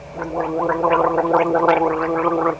Gargle Sound Effect
Download a high-quality gargle sound effect.
gargle-1.mp3